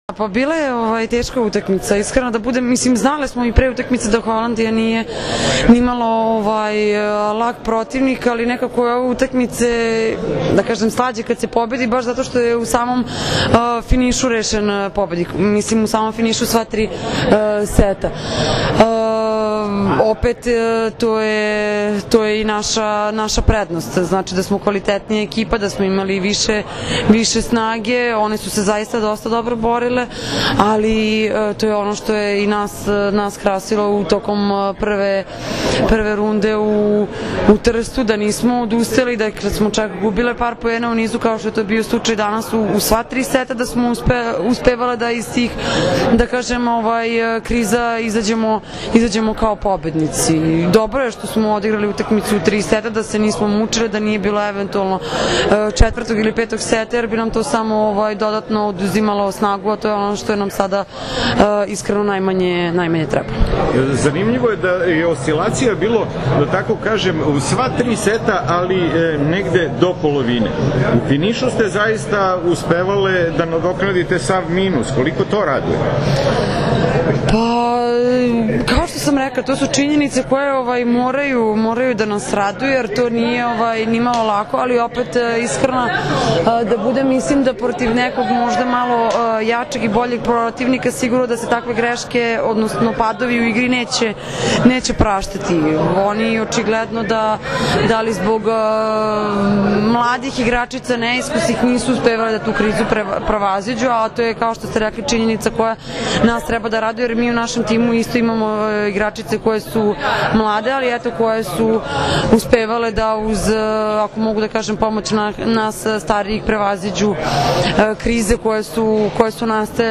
IZJAVA MAJE OGNJENOVIĆ